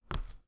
sfx_walk_ground_0.mp3